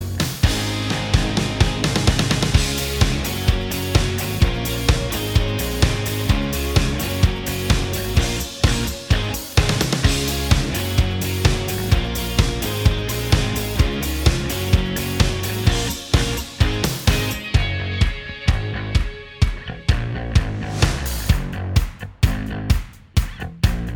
Minus All Guitars Pop (2010s) 3:20 Buy £1.50